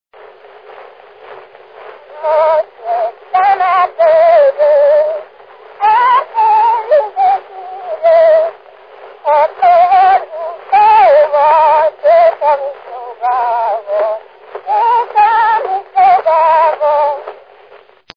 Erdély - Udvarhely vm. - Szentegyházasfalu
ének
Stílus: 8. Újszerű kisambitusú dallamok
Szótagszám: 6.6.6+6.6
Kadencia: 1 (2) V 1